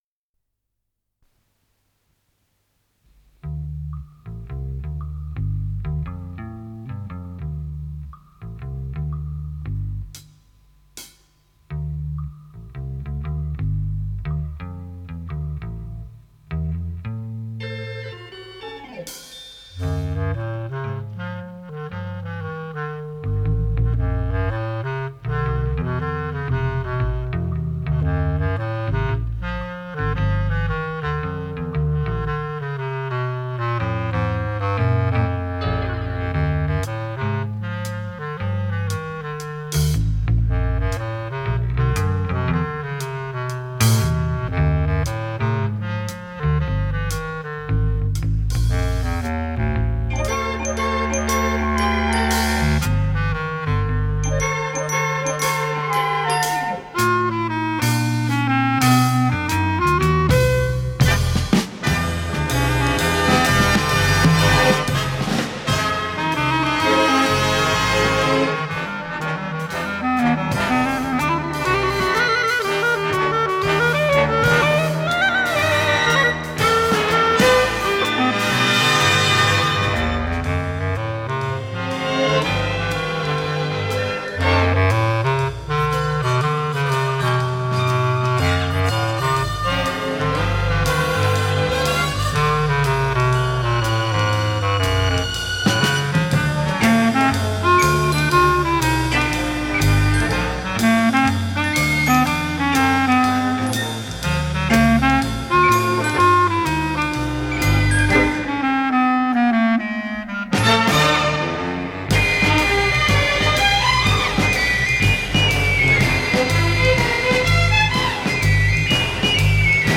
с профессиональной магнитной ленты
бас-кларнет
ВариантДубль моно